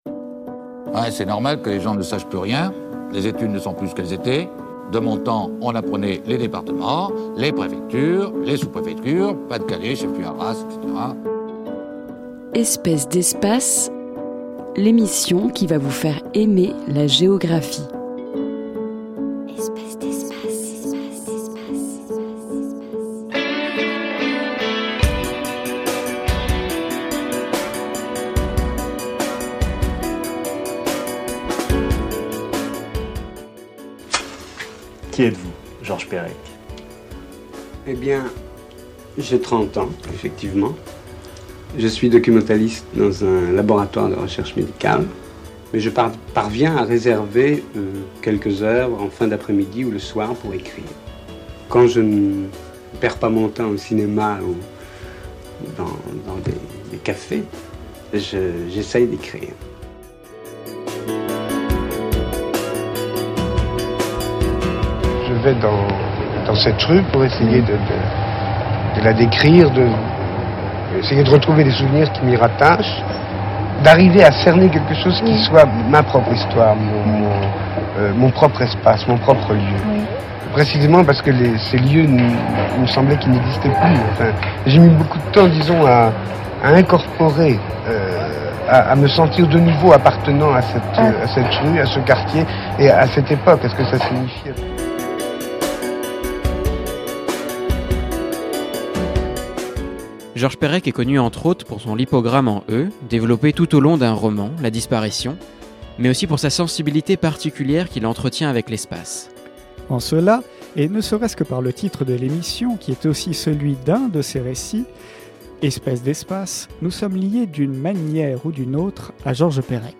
Pour parler de Georges Perec, en plus reprendre le lieu qu'il avait observé en 1974 dans Tentative d'épuisement d'un lieu parisien (place Saint Sulpice, 6e), nous avons en effet décidé de faire appel à deux interventions extérieures. Au programme de l'émission, une chanson composée et interprétée par